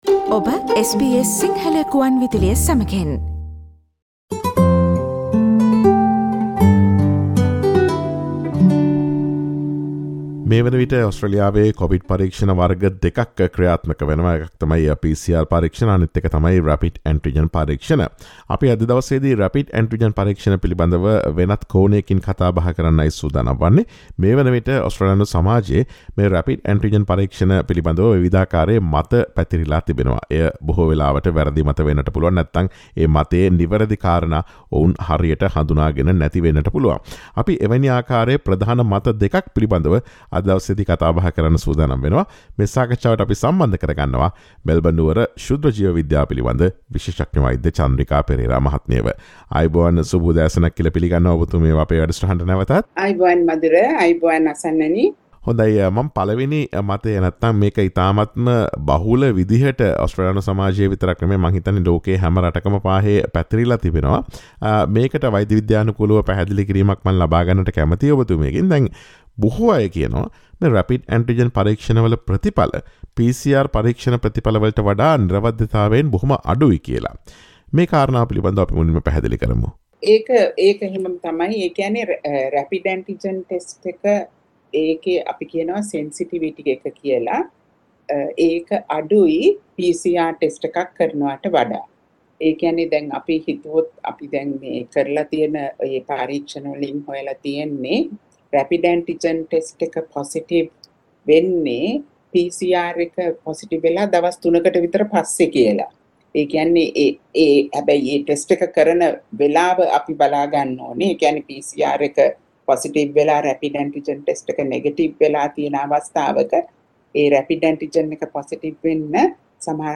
Rapid Antigen පරීක්ෂණය ගැන ඔස්ට්‍රේලියාවේ ඇති ප්‍රධාන මිථ්‍යාවන් පිළිබඳ SBS සිංහල ගුවන් විදුලිය සිදුකළ සාකච්ඡාවට සවන් දීමට ඉහත ඡායාරූපය මත ඇති speaker සලකුණ මත click කරන්න.